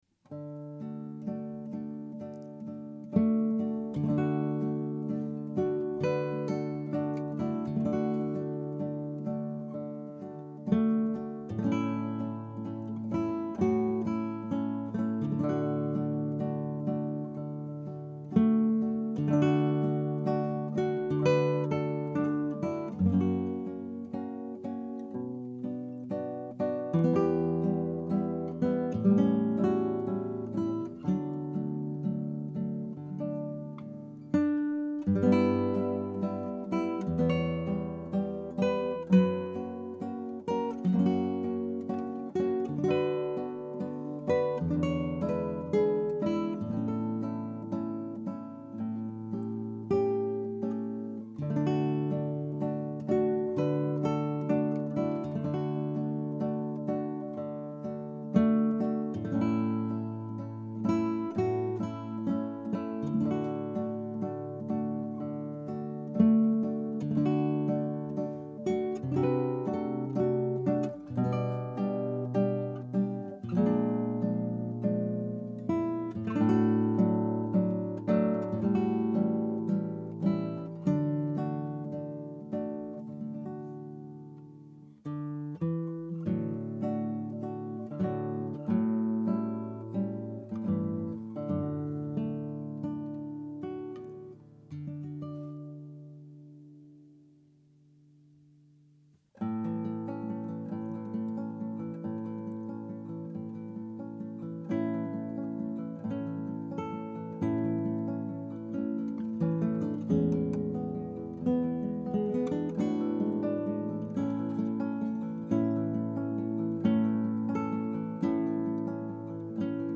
Scraps from the Operas arranged for Two Guitars
Scrap 1: Andantino.
Scrap 2 (1:39): Cantabile.
Scrap 3 (3:21): Vivo.
This lively waltz is nowhere to be found in my "complete" recording of Maritana.